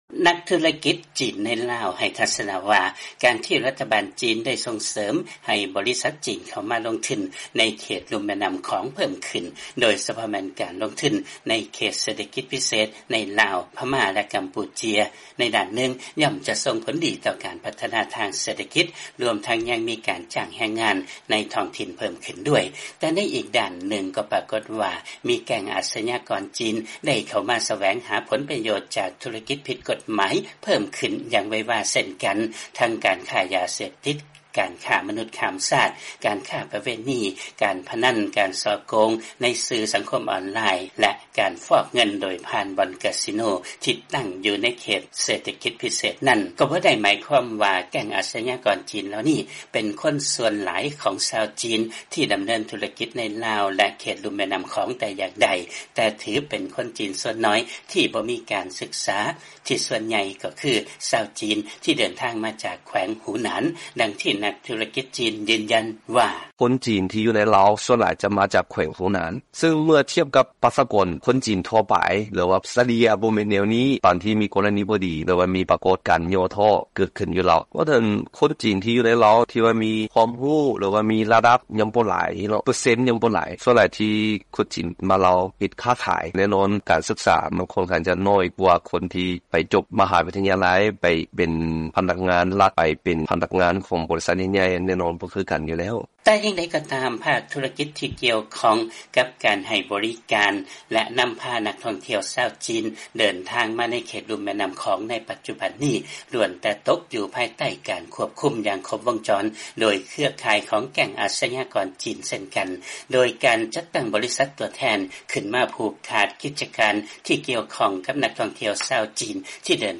ຟັງລາຍງານ ແກັ່ງອາຊະຍາກອນຈີນ ທີ່ດຳເນີນທຸລະກິດ ຜິດກົດໝາຍໃນເຂດລຸ່ມແມ່ນ້ຳຂອງ ສ່ວນໃຫຍ່ເປັນພວກທີ່ບໍ່ມີການສຶກສາ ມາຈາກແຂວງຫູໜານ